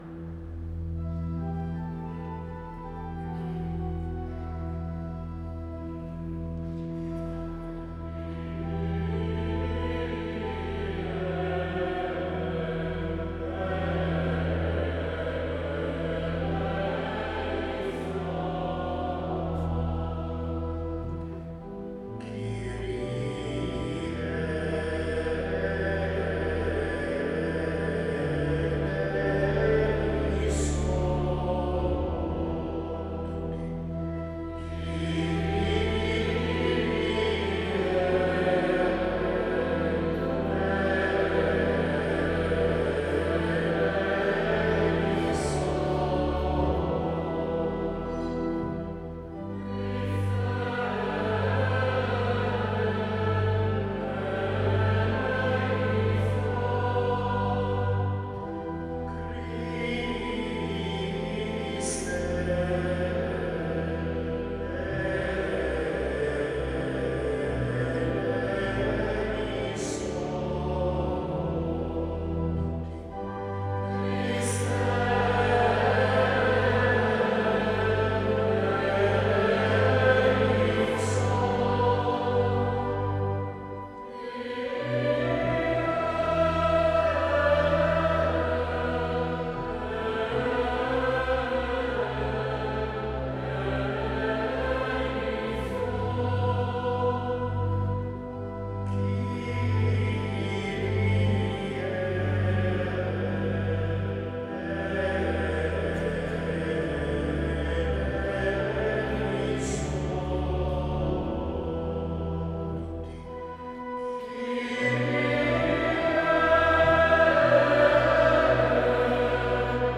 Gallery >> Audio >> Audio2016 >> Messa Crismale >> 02-Kyrie MessaCrisma2016
02-Kyrie MessaCrisma2016